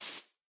grass3.ogg